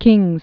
(kĭngz)